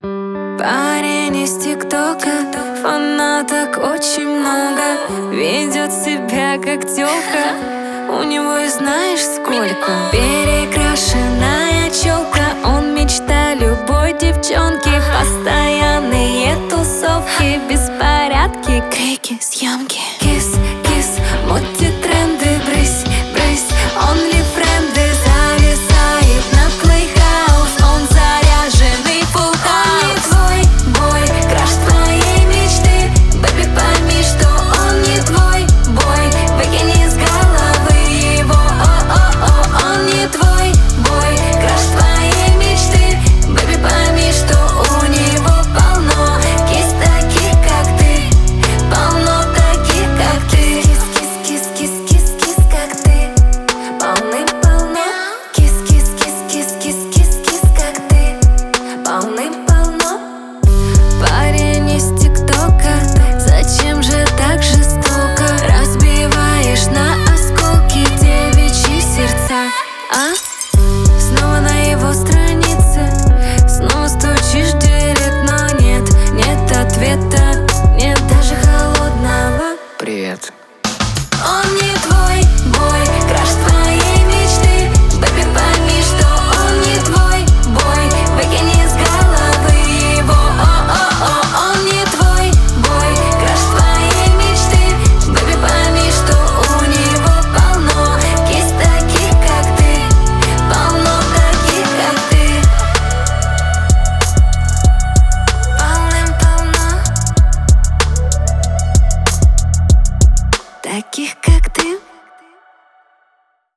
яркая поп-песня